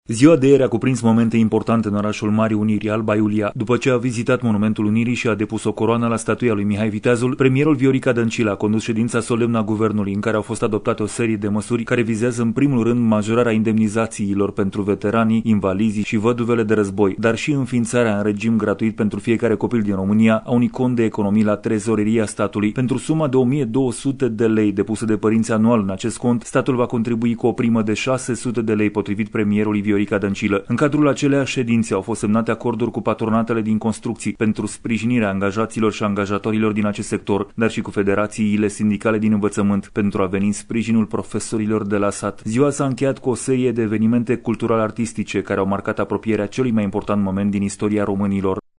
Transmite corespondentul RRA